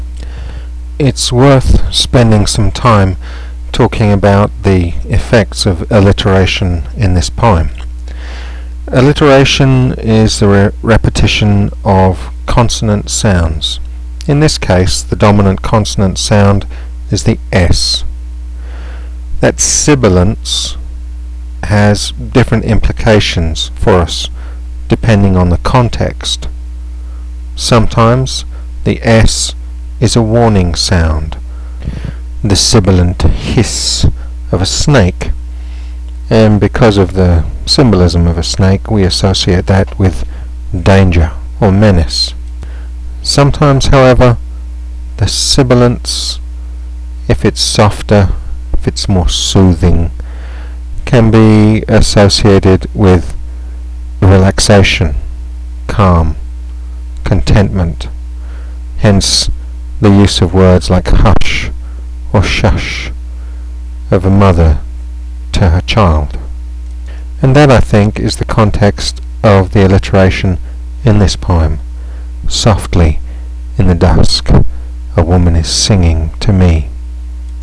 The "s" alliteration in the first line lulls us with its sibilant shushing noise and prepares us for the leap into childhood.
sibilance.wav